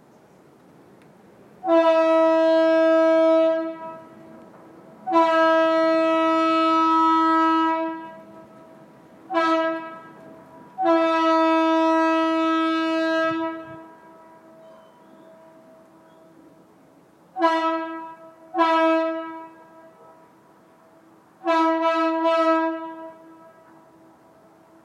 Over the course of several months I pieced this single chime SuperTyphon together and painted it to represent the single note horns that were once on Conrail switchers.